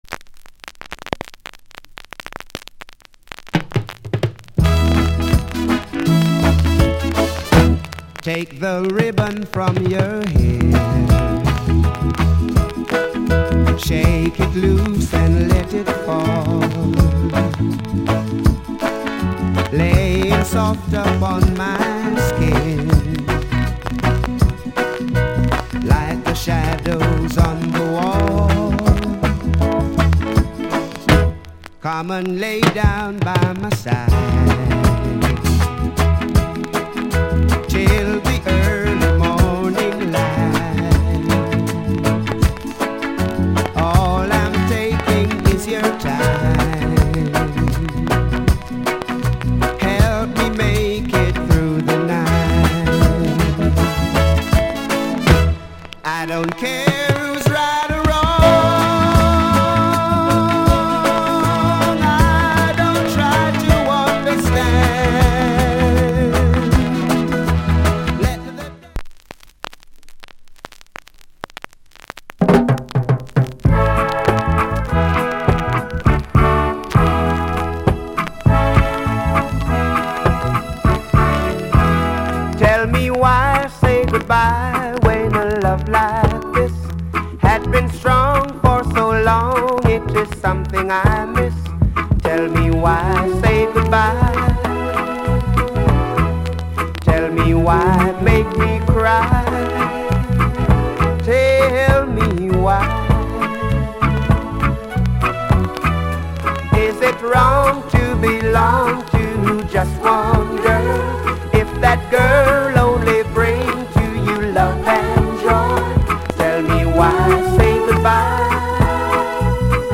Genre Reggae70sEarly / Male Vocal